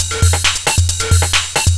DS 135-BPM A2.wav